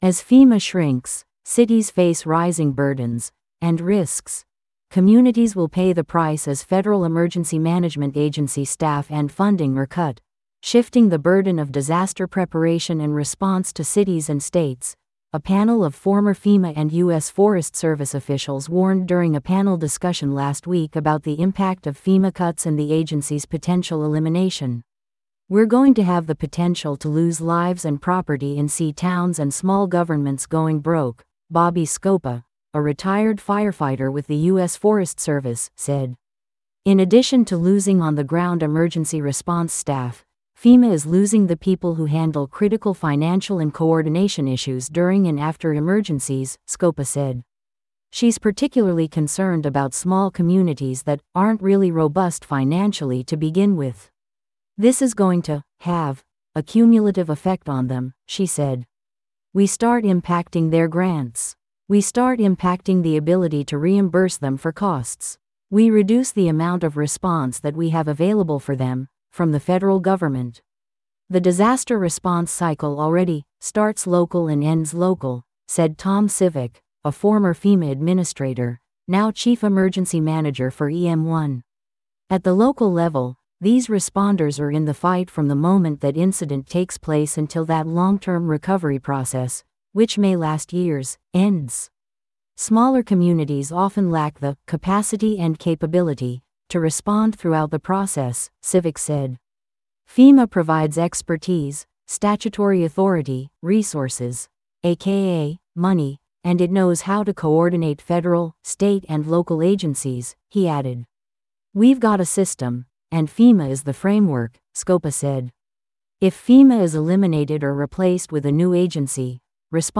This audio is auto-generated.
as-fema-shrinks-cities-face-rising-burdens-risks-natural-disasters_l5aod9.wav